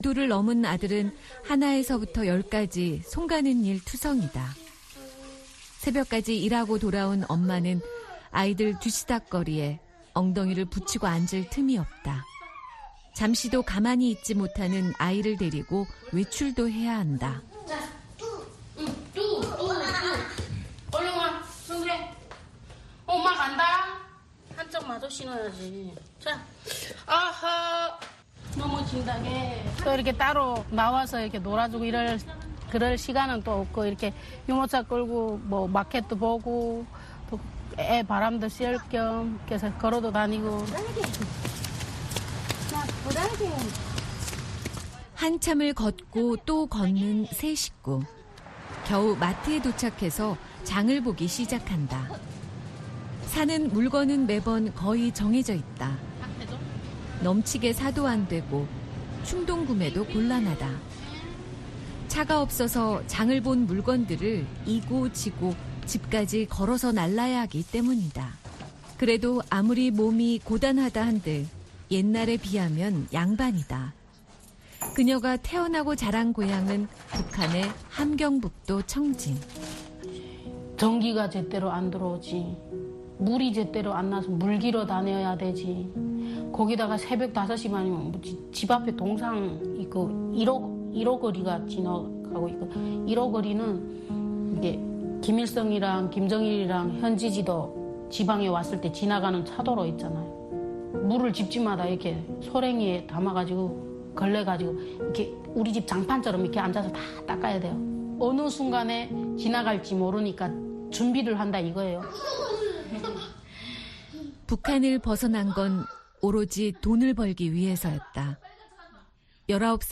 VOA 한국어 방송의 일요일 오전 프로그램 2부입니다. 한반도 시간 오전 5:00 부터 6:00 까지 방송됩니다.